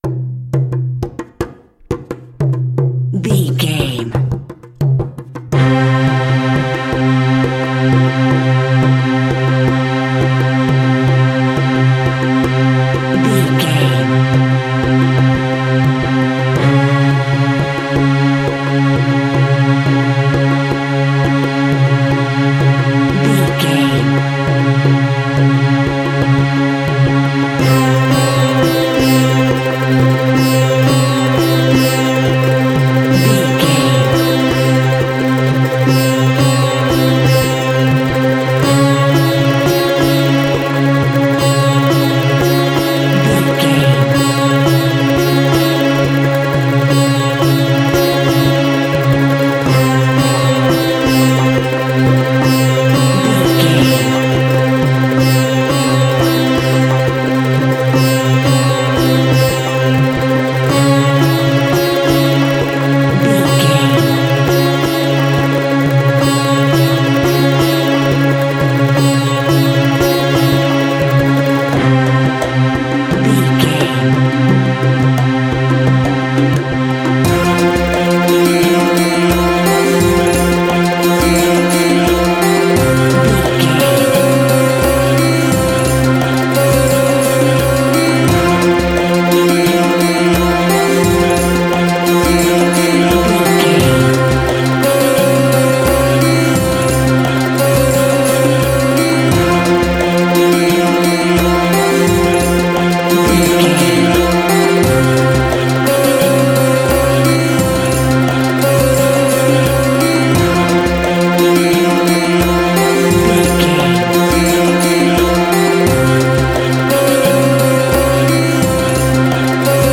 Aeolian/Minor
sitar
bongos
sarod
tambura